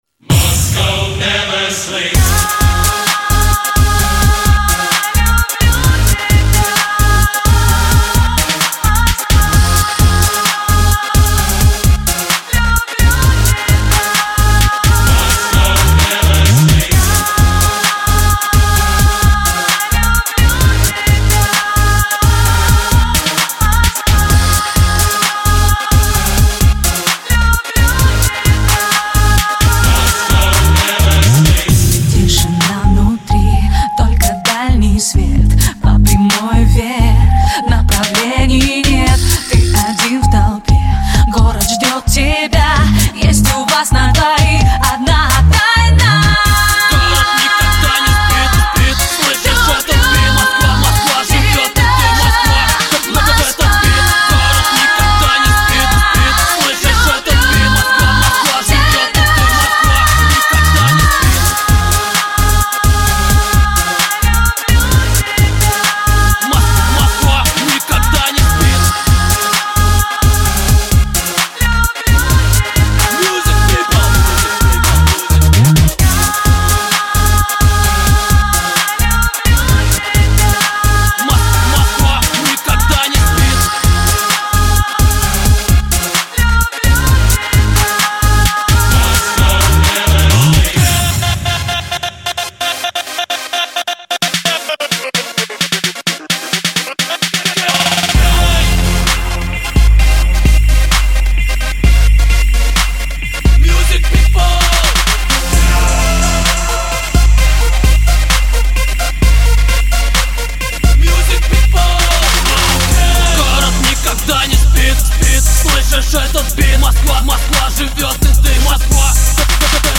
Назад в ¤Super / Club / Dance¤
*** Друзья новинка для любителей RnB ***